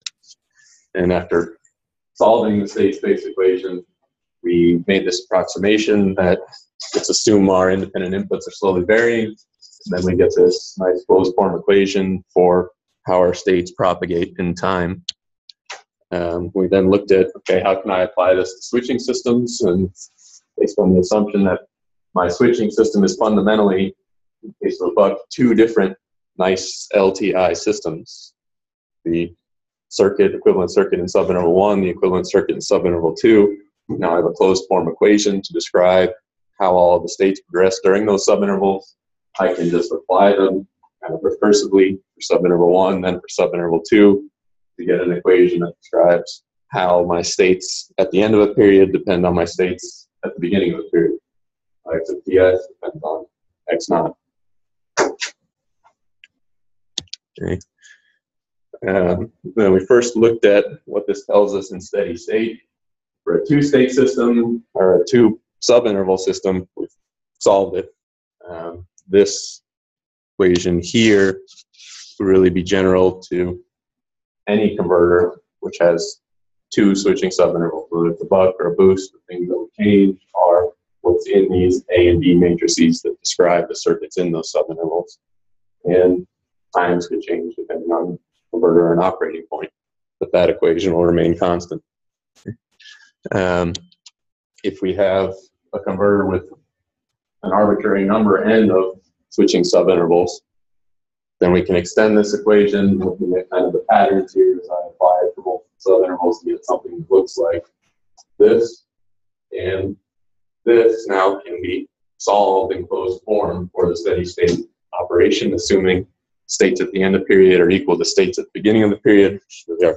Lecture recordings Recording